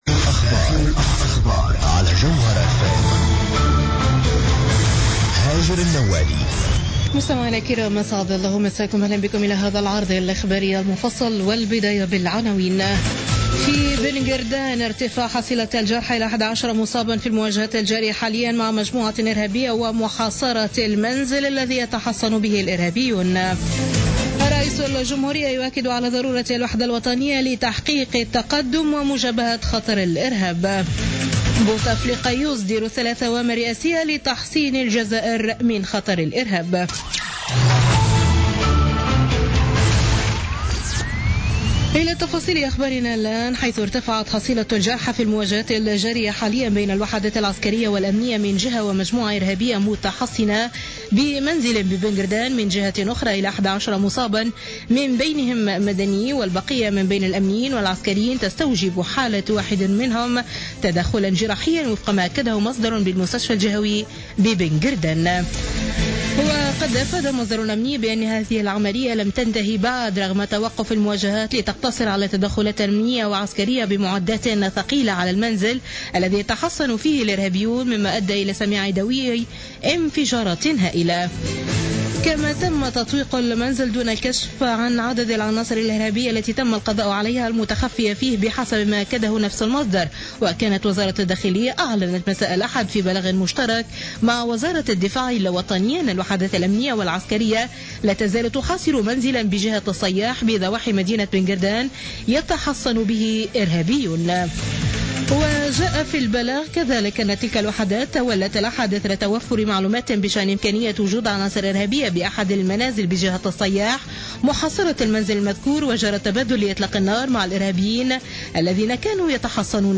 نشرة أخبار منتصف الليل ليوم الاثنين 21 مارس 2016